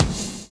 VINYL 4 BD.wav